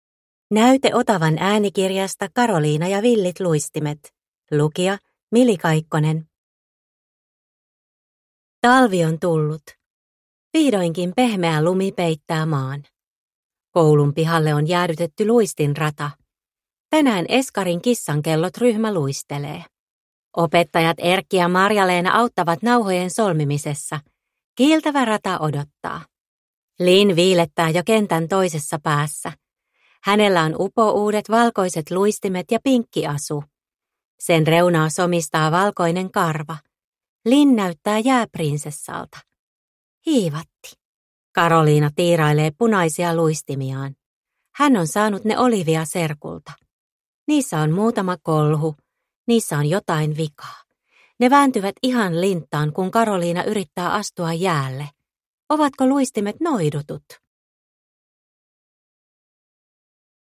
Karoliina ja villit luistimet – Ljudbok – Laddas ner